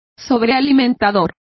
Complete with pronunciation of the translation of supercharger.